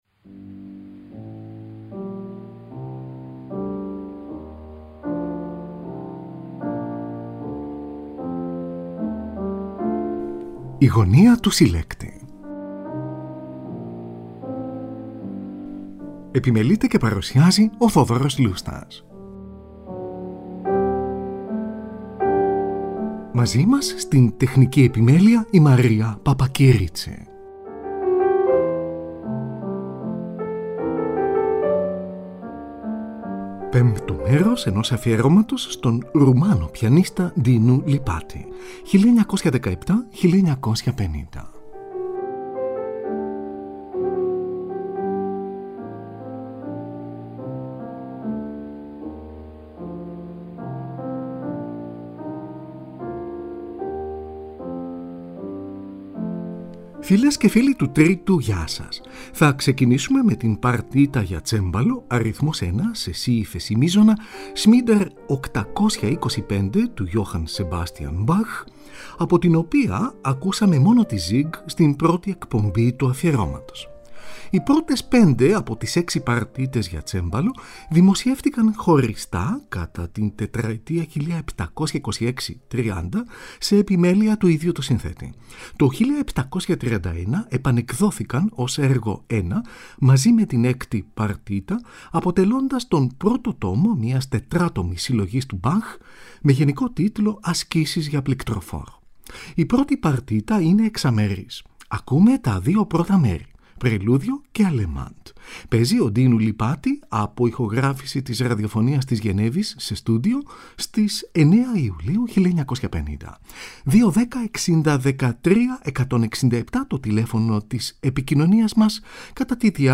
παρτίτα για τσέμπαλο αρ.1
σονάτα για πληκτροφόρο σε λα ελάσσονα